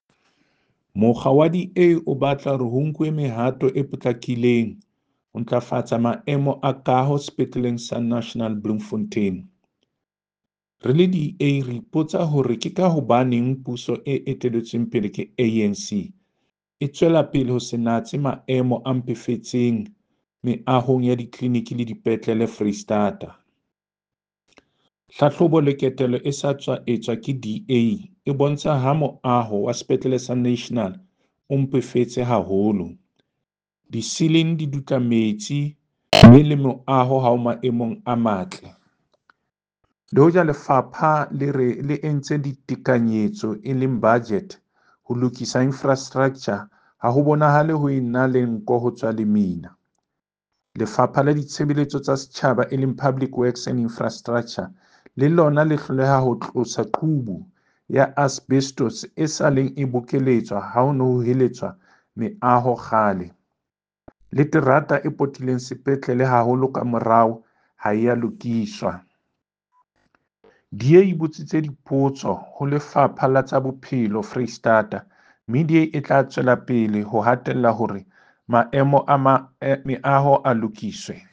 Sesotho soundbites by David Masoeu MPL and